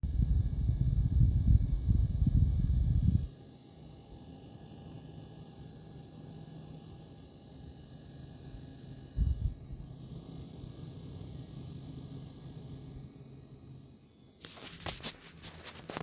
Problem: Sobald ich ein Spiel starte, fängt meine Grafikkarte laut an zu surren bzw. krächzen. Aufnahme mit Handy im Anhang (Handy an Grafikkarte gehalten).
Die Lautstärke ist deutlich hörbar (auch bei geschlossenem Gehäuse). Sie ist außerdem konstant gleichlaut .
Zu dem Surren/Krächzen kommt manchmal ein lautes Knacken hinzu, welches eher selten auftaucht. Es klingt so, als würde etwas mechanisch umgeschaltet werden.